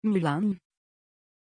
Pronunciation of Mylann
pronunciation-mylann-tr.mp3